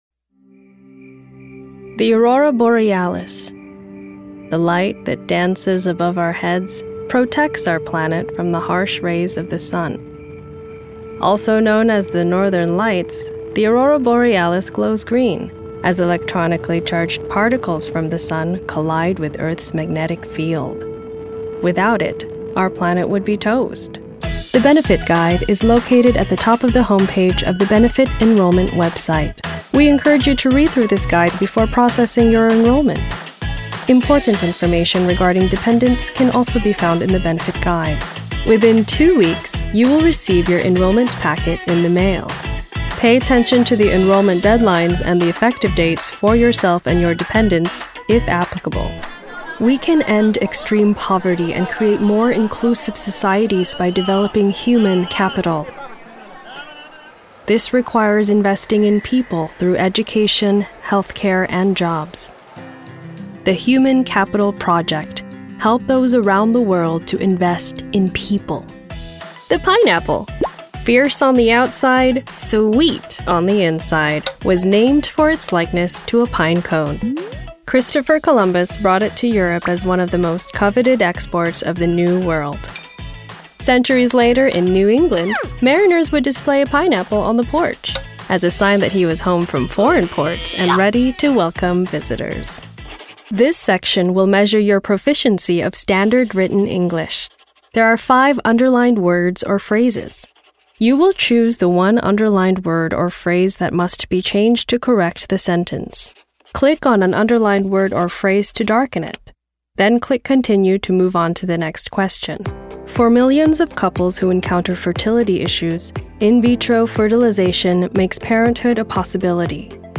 As youthful as I sound, my talent has been ripened through extensive education, training, work and life experience, and a driven dedication to your story’s sweet success.
Adult, Young Adult Has Own Studio
Location: New York, NY, USA Languages: english 123 tagalog Accents: Tagalog standard us Voice Filters: VOICEOVER GENRE commercial e-learning